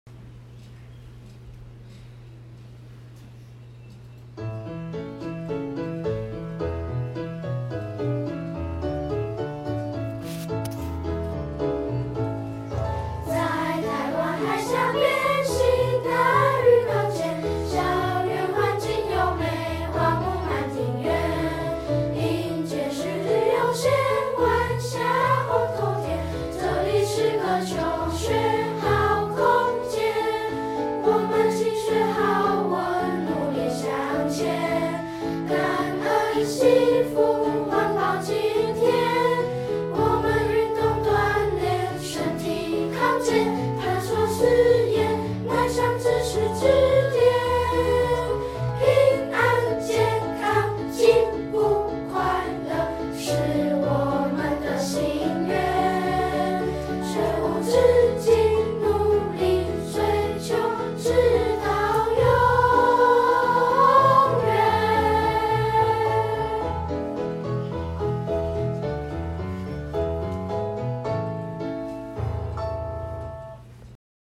校歌_合唱2.mp3.mp3